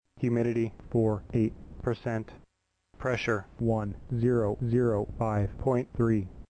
ws1-voice.mp3